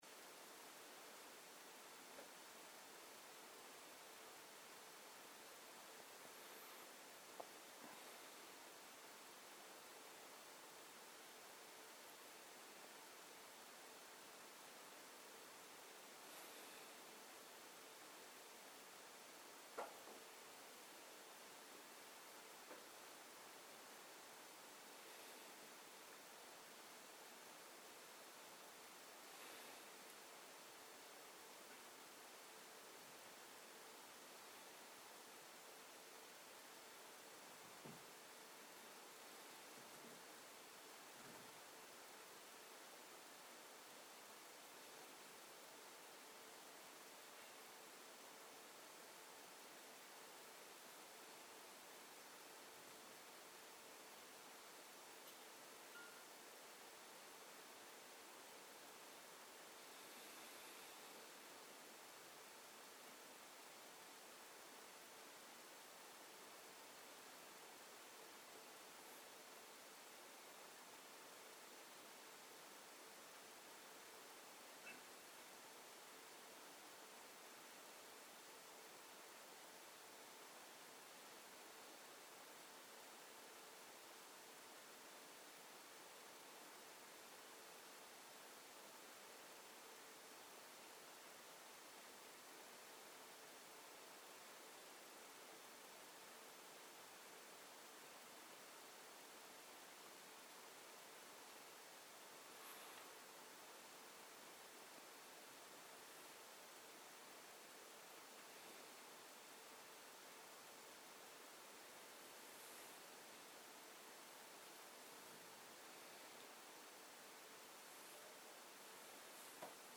מדיטציה מונחית - מטא - אחר הצהרים
סוג ההקלטה: מדיטציה מונחית